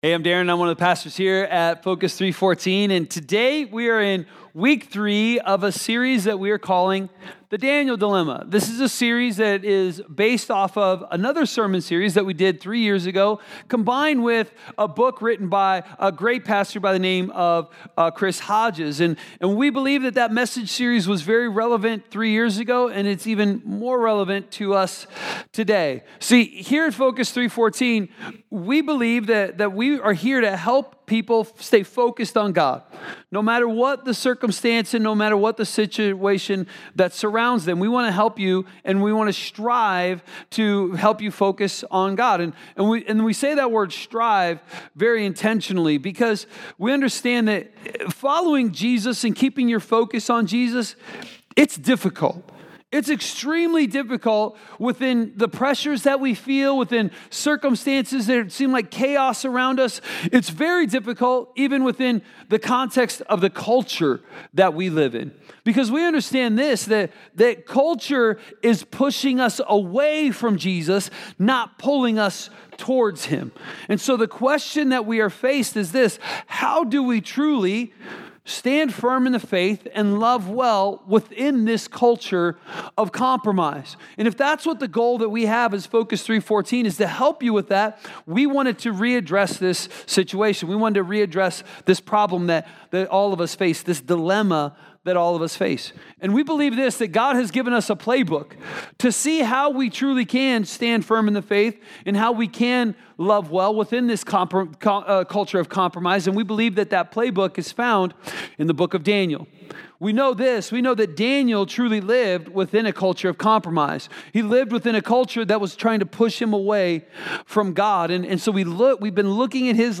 A message from the series "The Daniel Dilemma." How do you hold on to God's truth in a culture of compromise?